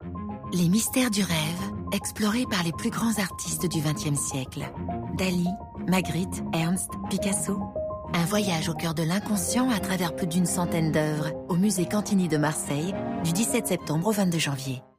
douce // dans l'oreille